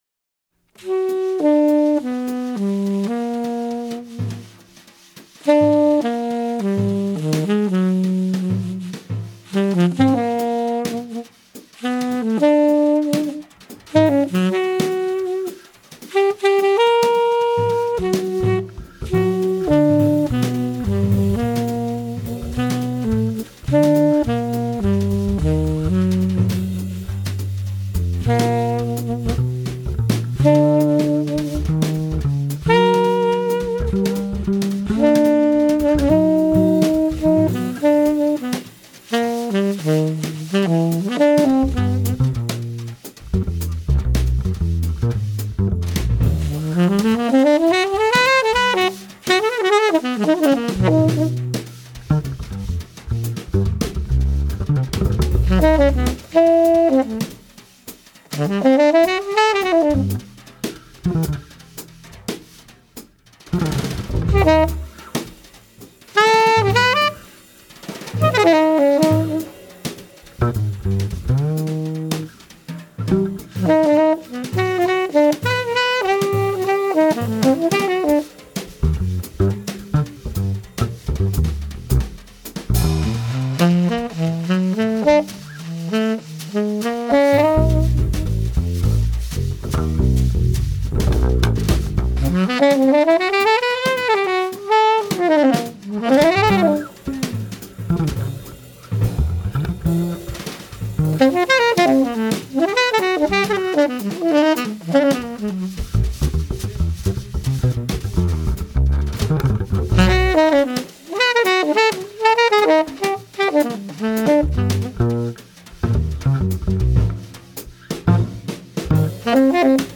tenor sax
acoustic bass
drums